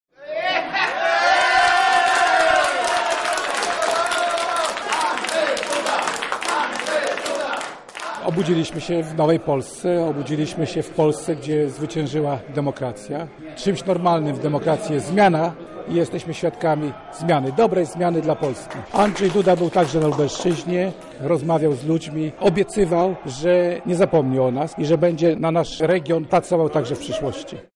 Mówił Krzysztof Michałkiewicz szef lubelskigo PiS.